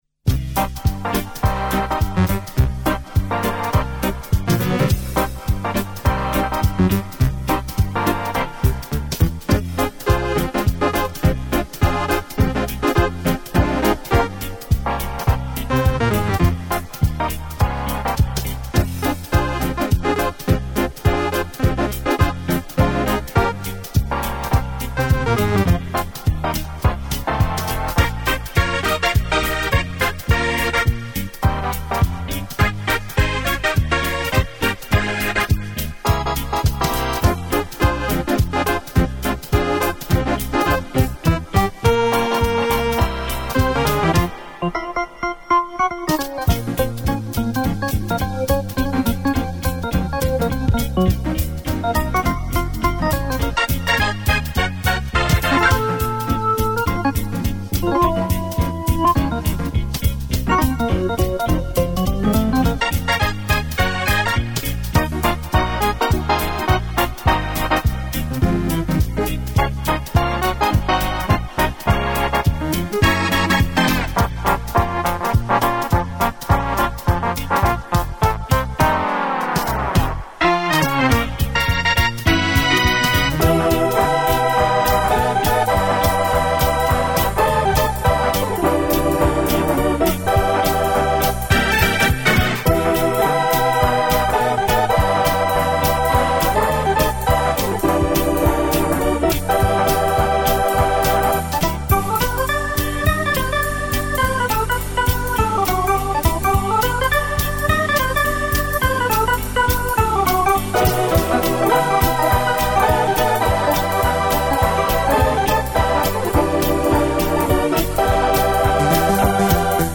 但传统的电子琴音乐现在听起来是依然非常好听。他有一种非常神奇的音质，在似与不似之间。